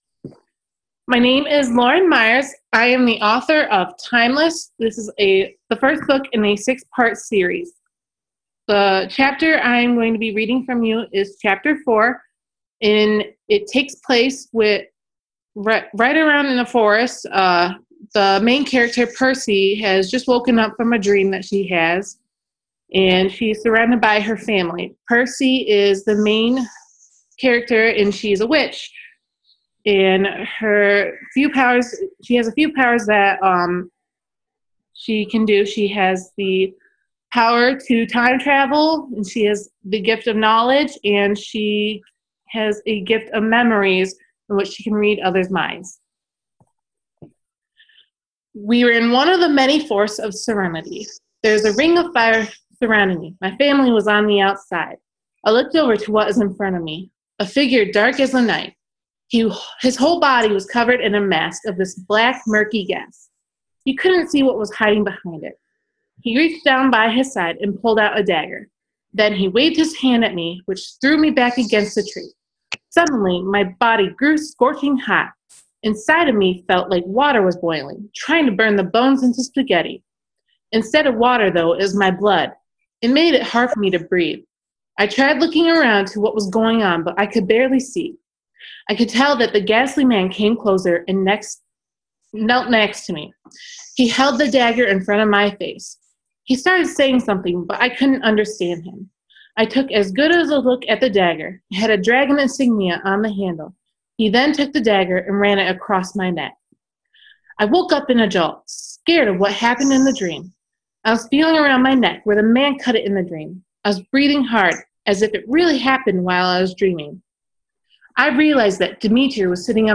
An excerpt reading